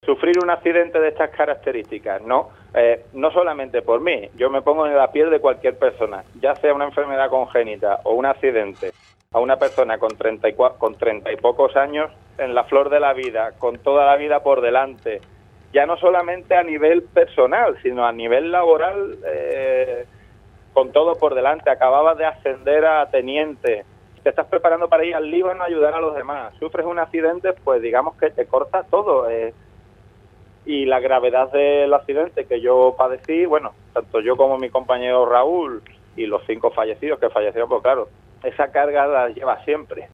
casi en un susurro (formato MP3).